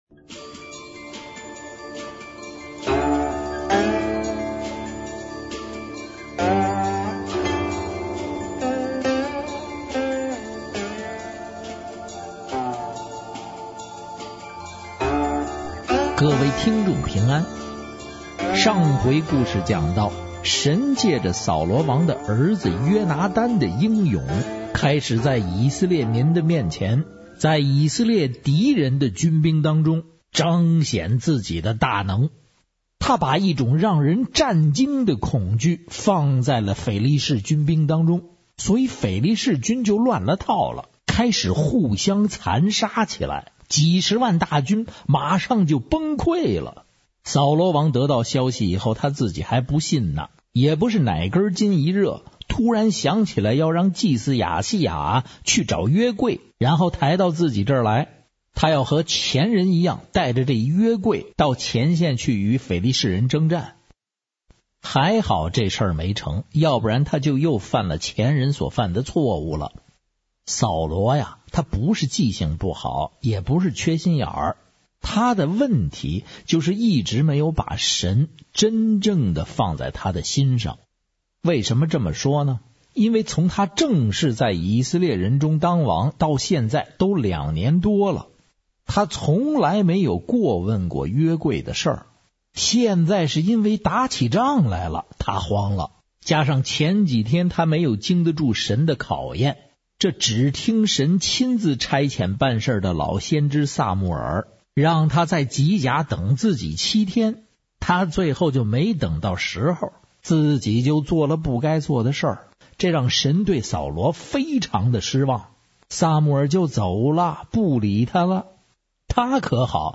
京腔京味。